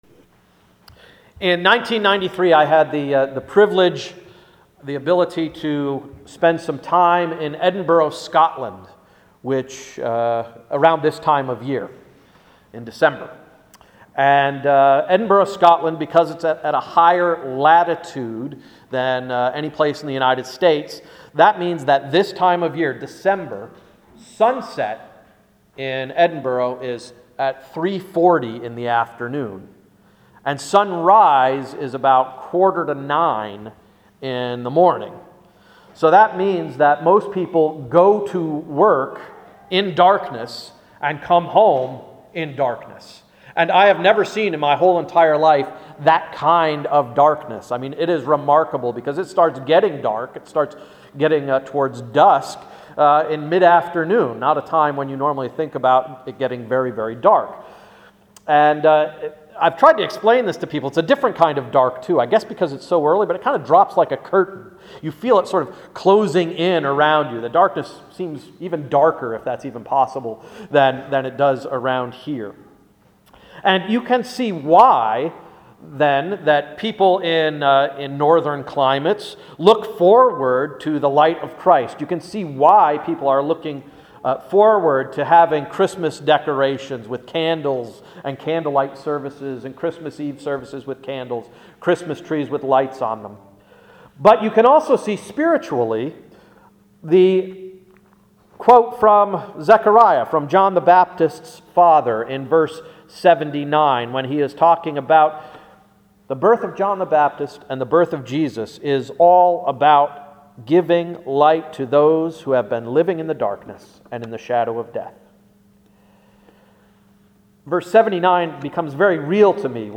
Sermon of December 9, 2012–“Reading the Introduction”